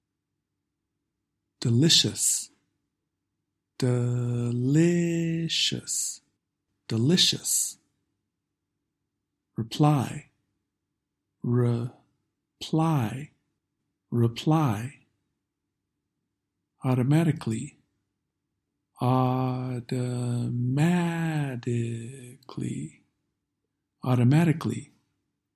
Vowels in unstressed syllables often change their pronunciation to /ə/ or /ɪ/.